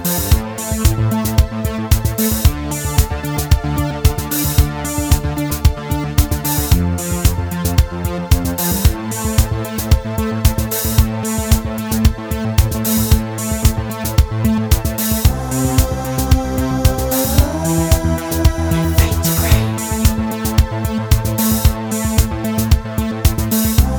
no French Girl Pop (1980s) 3:49 Buy £1.50